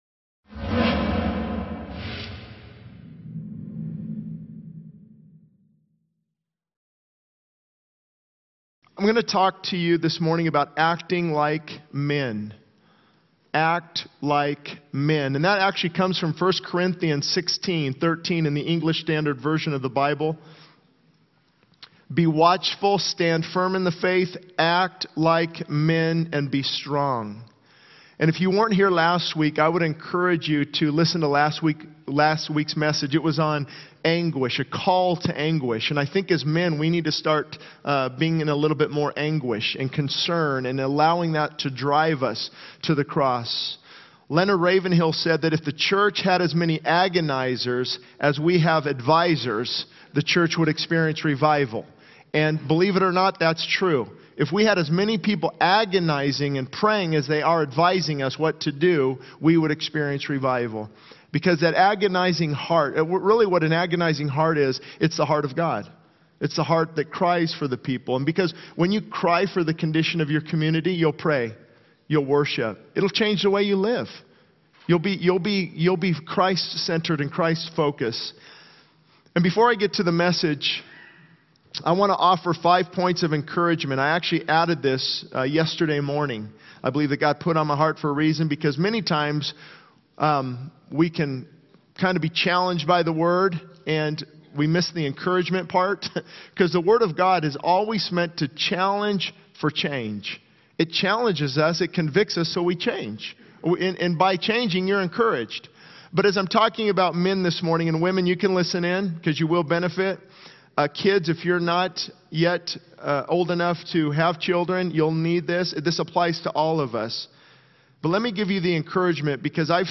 This sermon emphasizes the importance of men acting with strength and faith, urging them to be watchful, stand firm in the faith, act like men, and be strong. It highlights the need for men to embrace anguish and concern, to be agonizers in prayer for revival, and to have hearts that cry for the people.